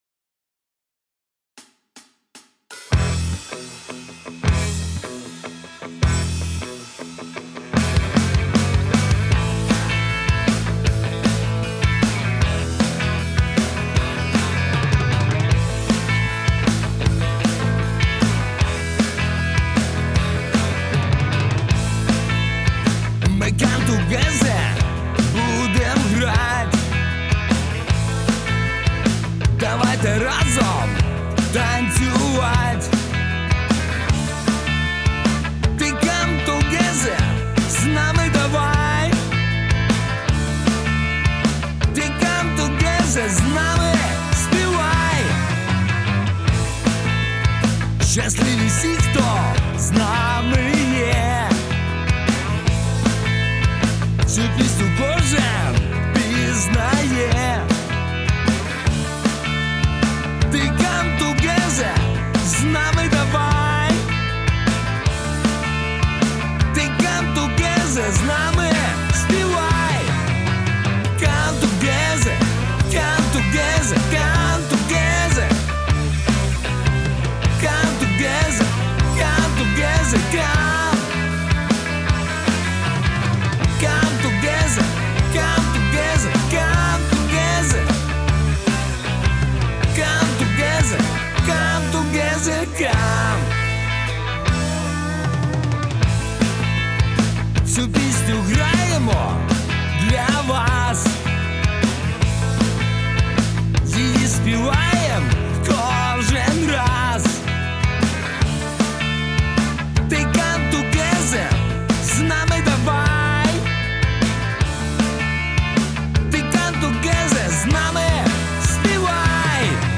Rock&Roll
Готов выслушать все минуса которые есть в данном сведении.